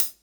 HAT POP HH07.wav